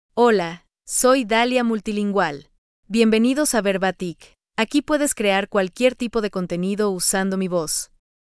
FemaleSpanish (Mexico)
Dalia Multilingual — Female Spanish AI voice
Dalia Multilingual is a female AI voice for Spanish (Mexico).
Voice sample
Listen to Dalia Multilingual's female Spanish voice.